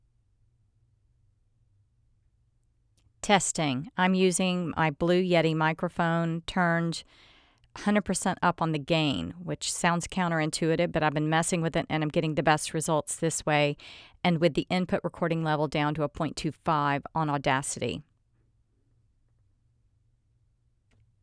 The voice is clearer, but there is still something going on there. You have a very slight processing honkiness.
Another name for this is talking into a wine glass voice. It’s the signature of noise reduction or environment processing.